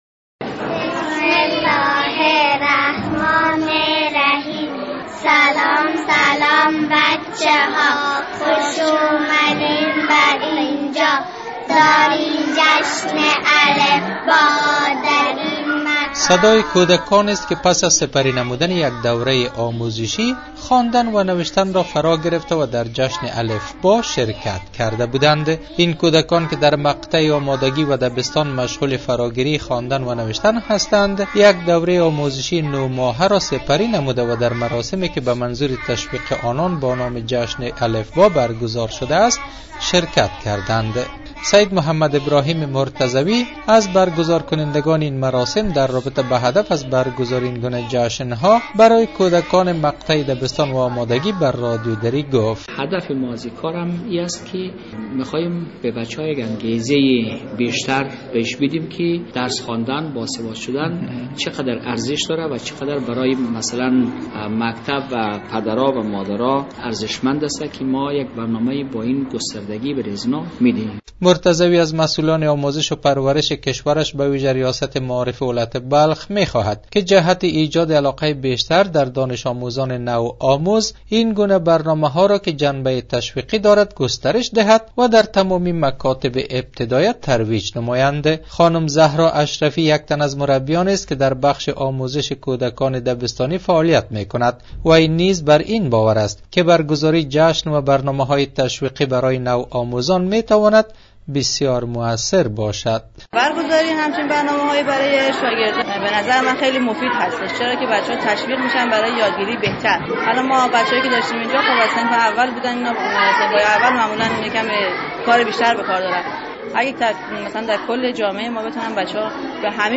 گزارش : برگزاری جشن الفبا در مزار شریف